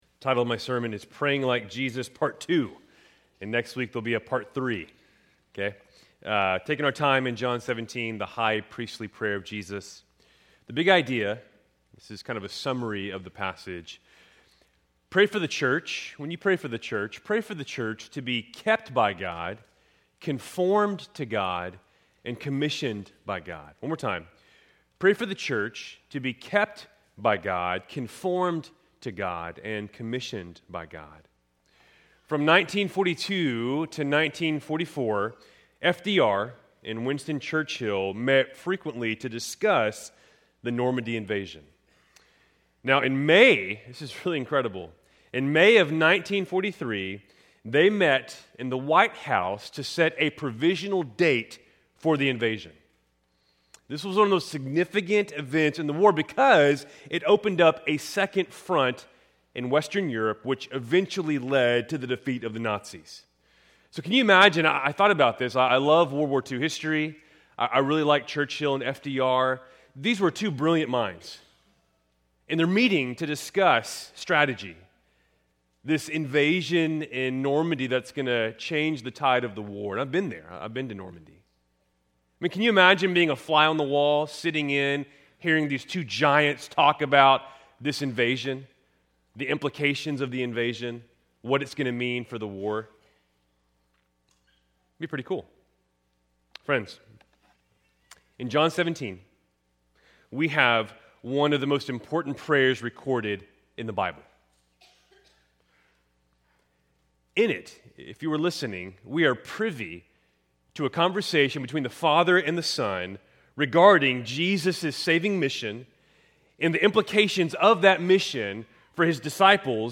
Keltys Worship Service, November 16, 2025